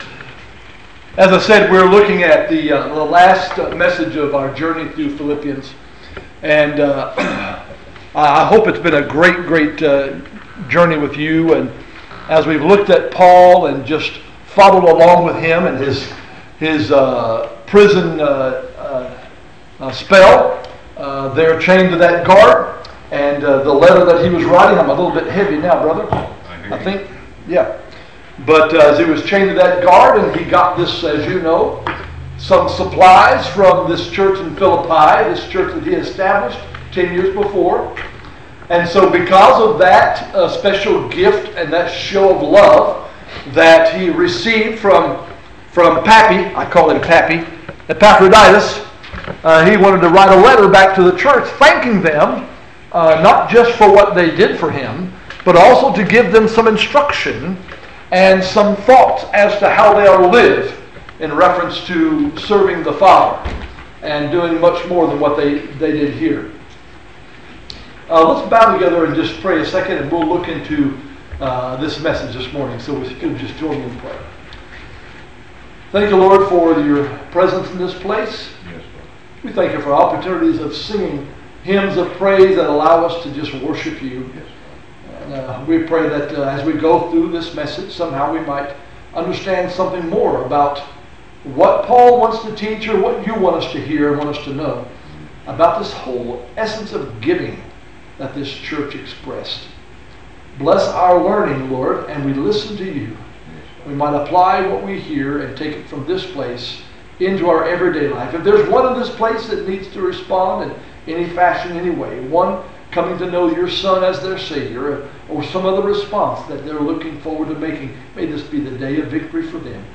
Sermons - Jackson Ridge Baptist Church